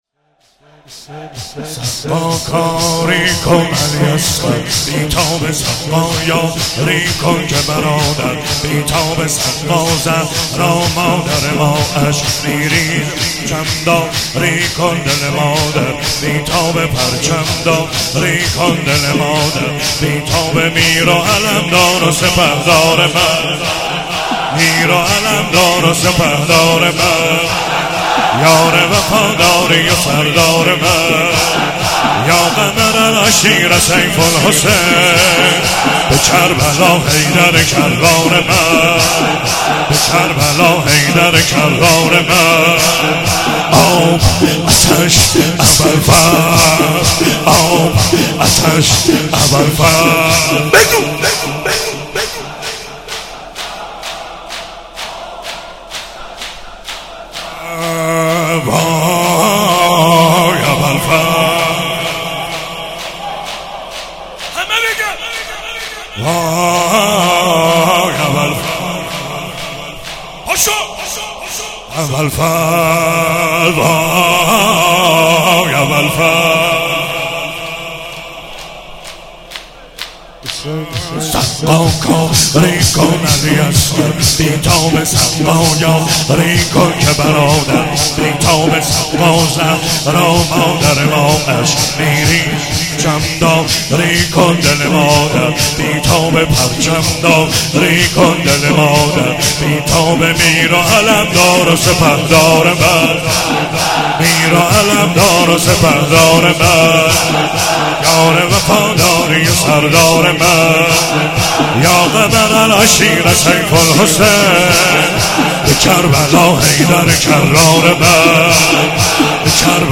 چهاراه شهید شیرودی حسینیه حضرت زینب (سلام الله علیها)
زمینه- ای آسمان قمر افتاده بر زمین